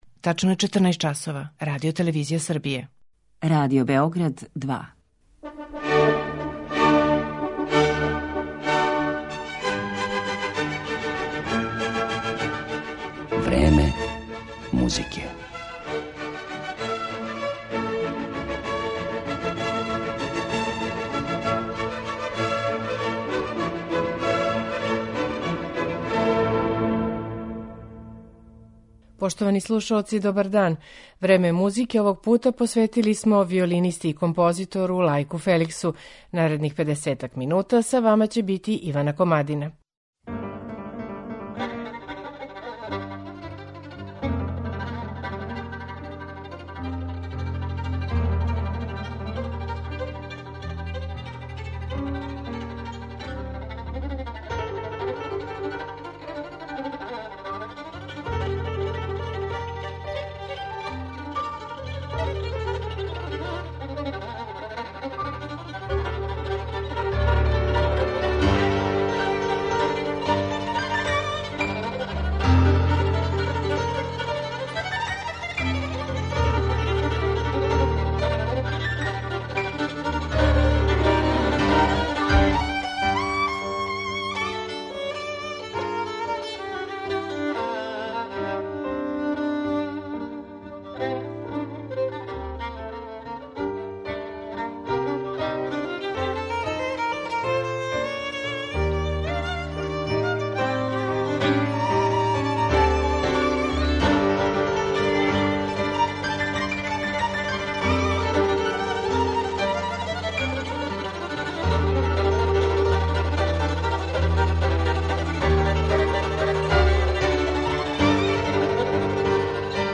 суботички виолиниста и композитор